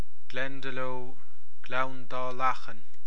Comhad Fuaime Foghraíochta